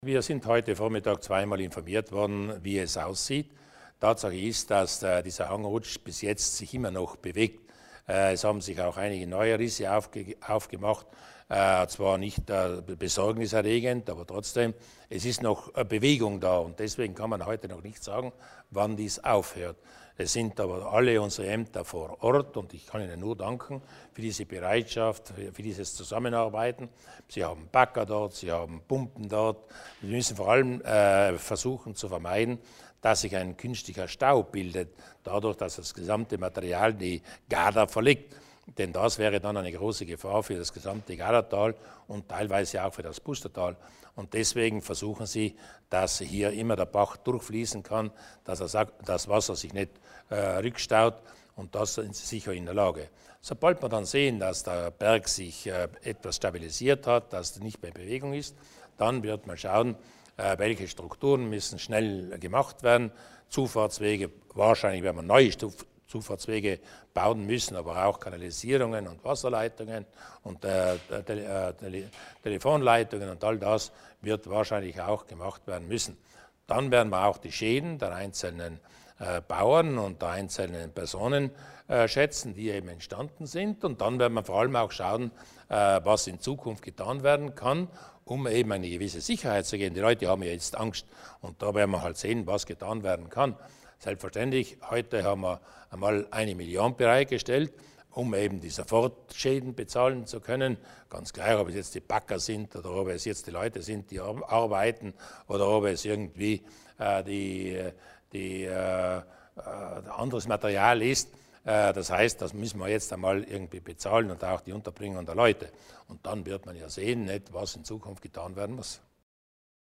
Landeshauptmann Durnwalder über die Situation in Hochbatei